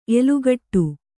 ♪ elugaṭṭu